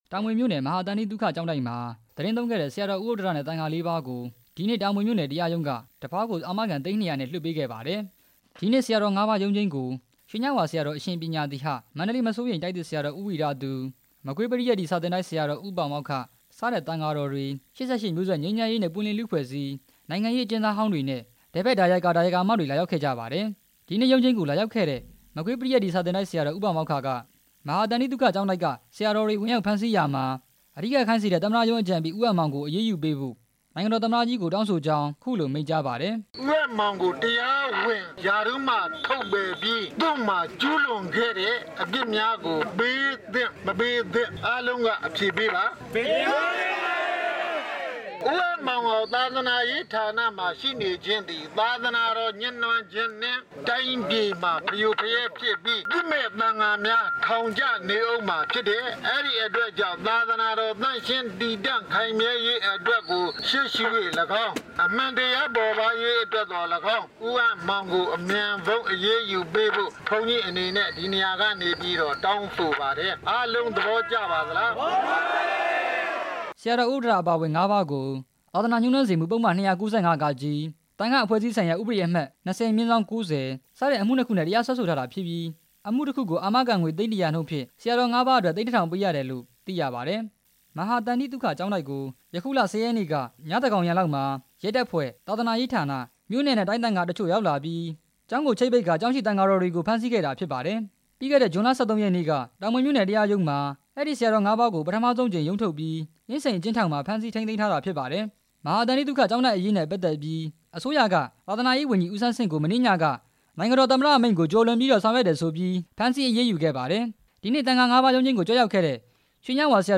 တင်ပြချက်